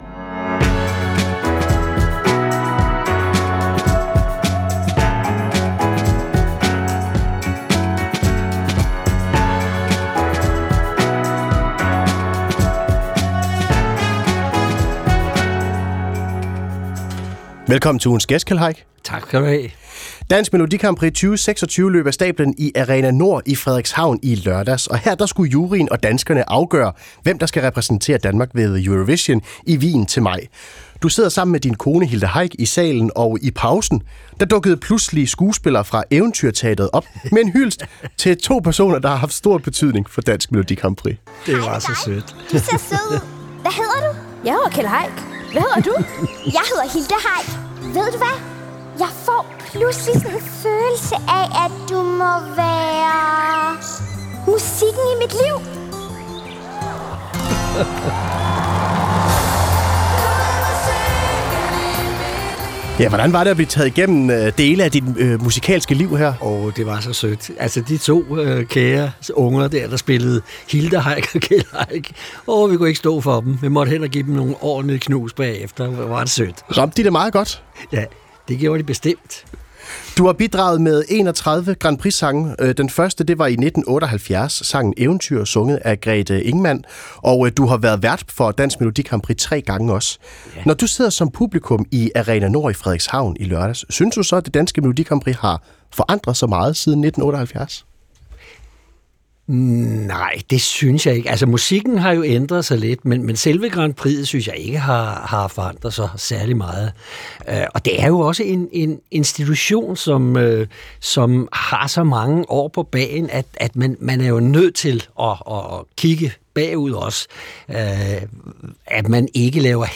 Ugens gæst